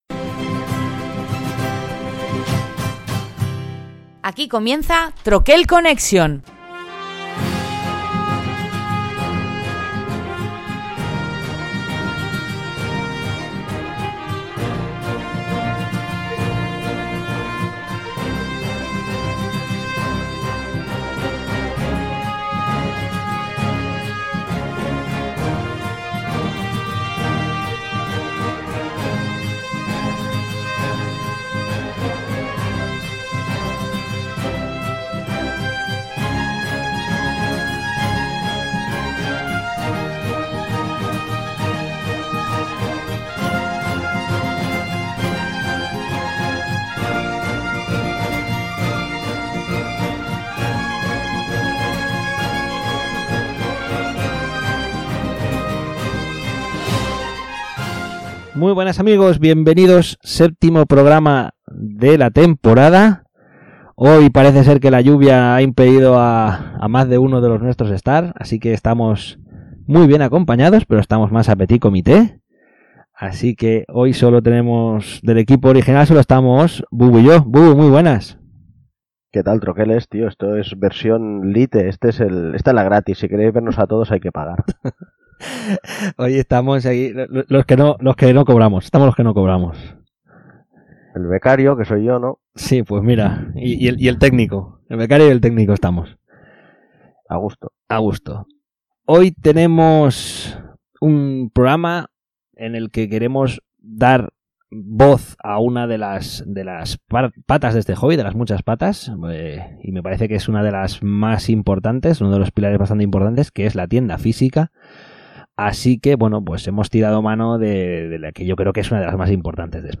Toda una tertulia amena y pausada sobre este mundillo con alguien que lo vive desde dentro y como jugona a la vez. un complicado equilibrio que ella maneja a la perfección.